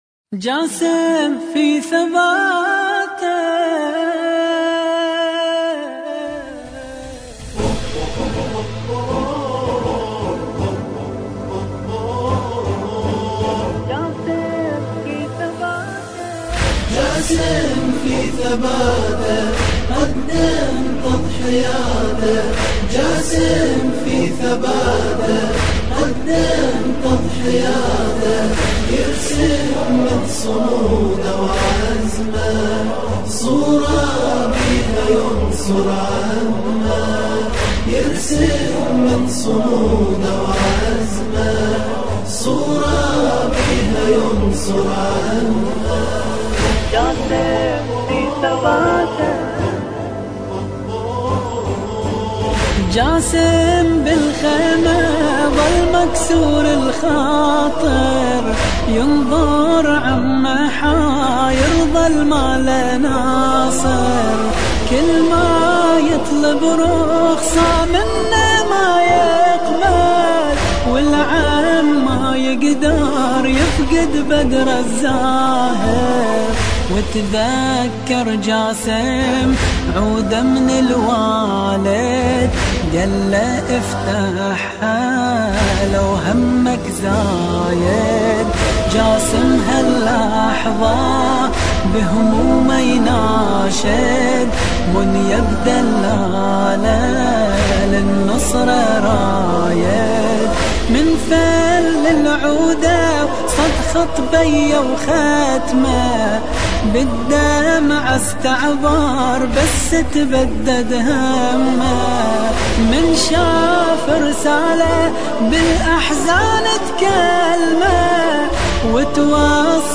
مراثي قاسم (ع)